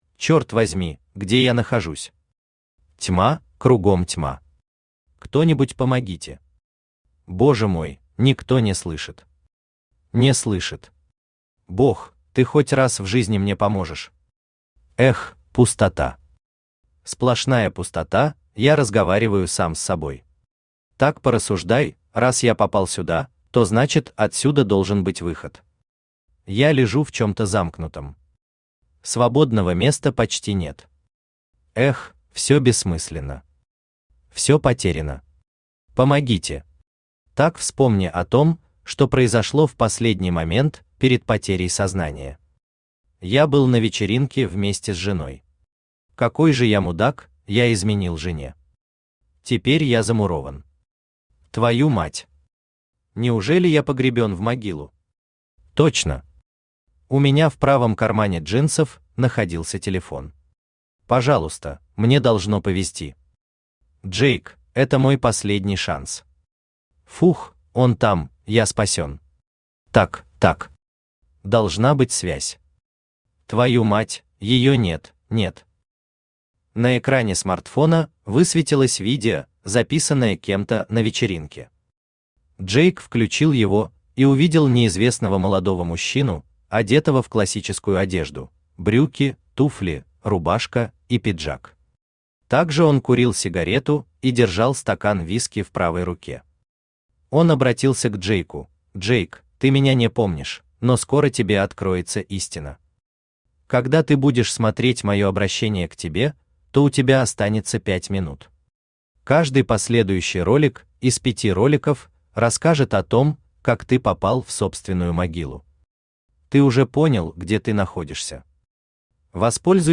Аудиокнига Распад